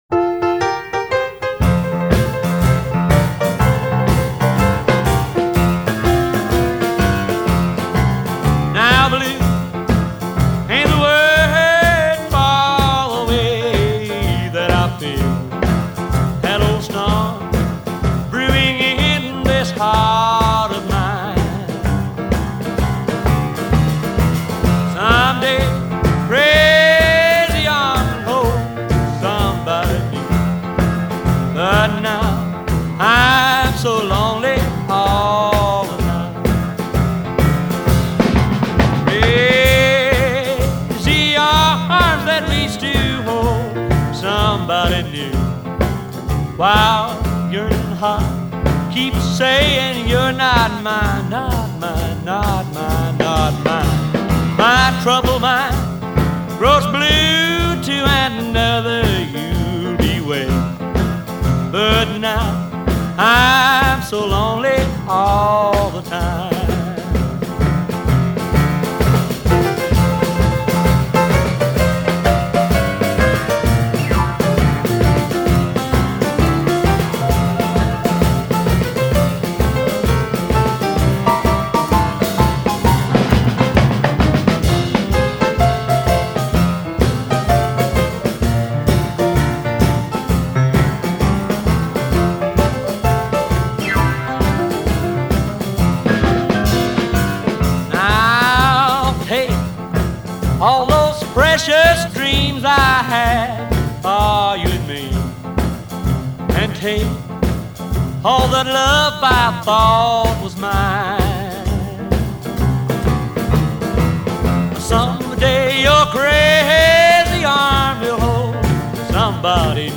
He could make the phone book sound sad.